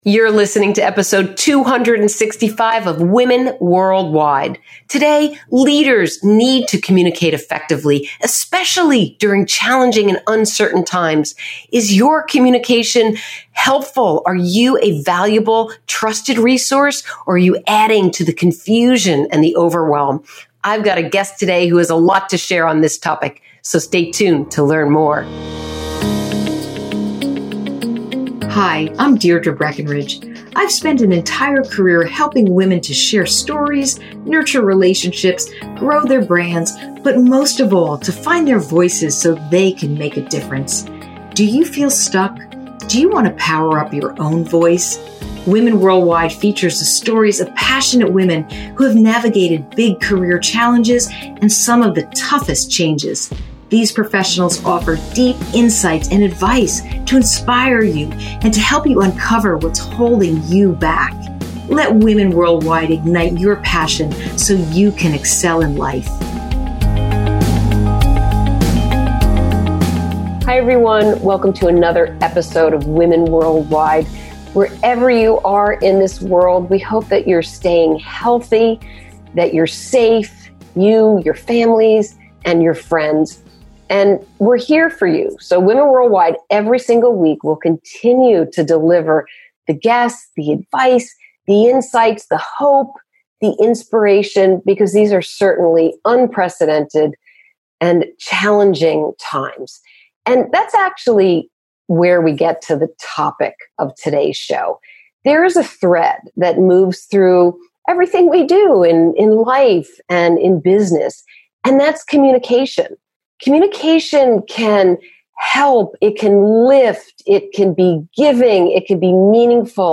*We apologize for any issues with sound quality. Due to the ever-changing state of the world because of Covid-19, many of our guests are taking interviews and recording shows outside of their homes which interferes with the recording.*